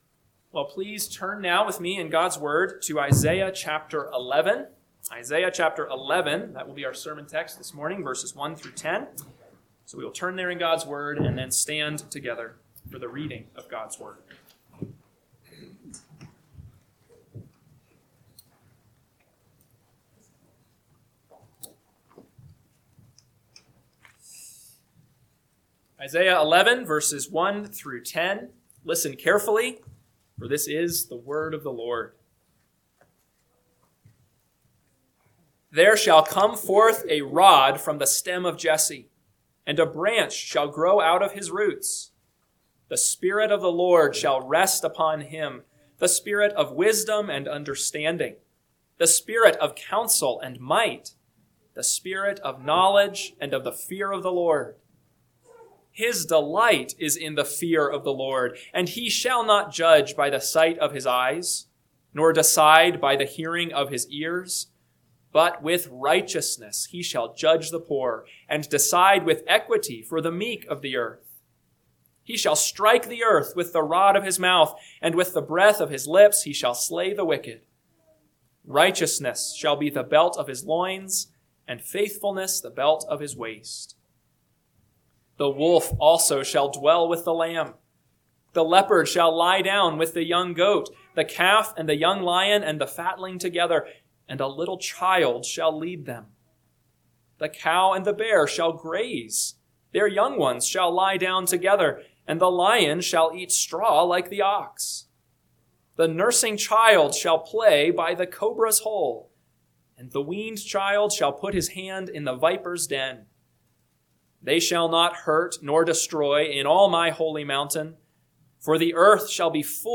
AM Sermon – 2/1/2026 – Isaiah 11:1-10 – Northwoods Sermons